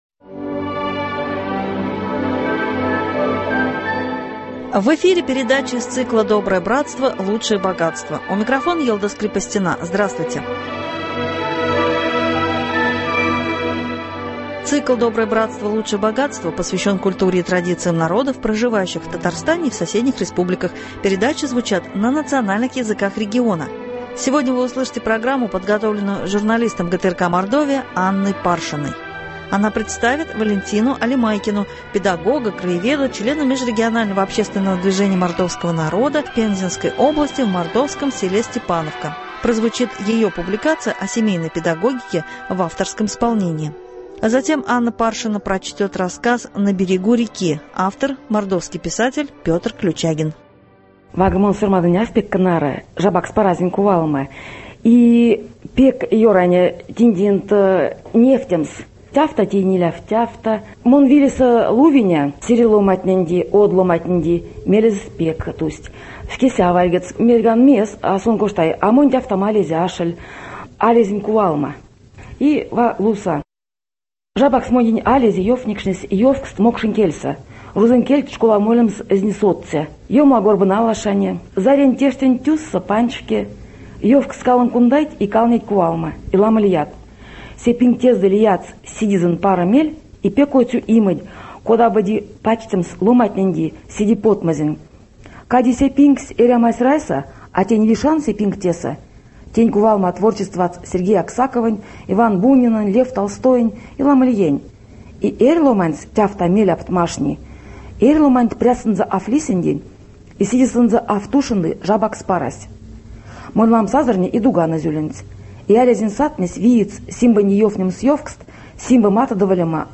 Прозвучит ее публикация о семейной педагогике в авторском исполнении.